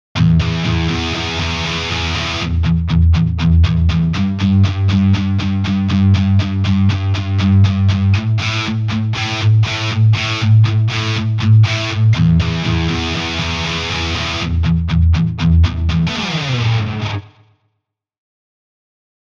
Im nächsten Beispiel spielen Patterns aus der Kategorie „Riff“ mit Amp-Distortion und Pedal-Effekten:
02_heavy.mp3